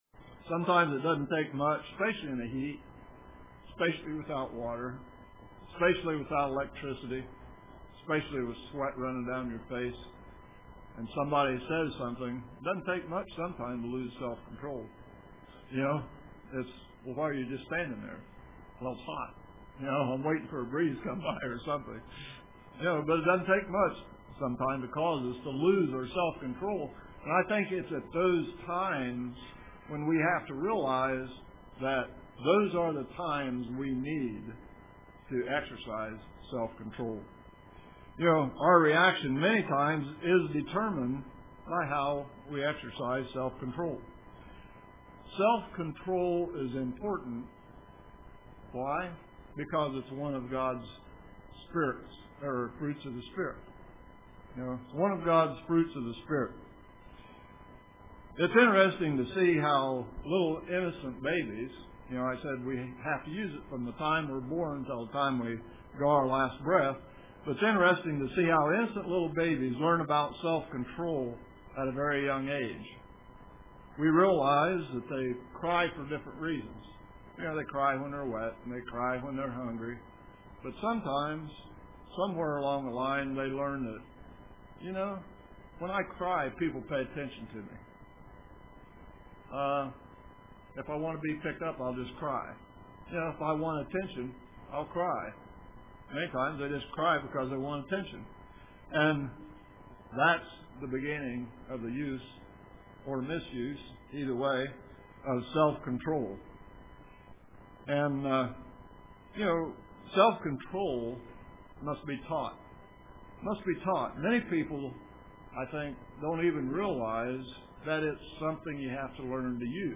How to Use Self Control UCG Sermon Studying the bible?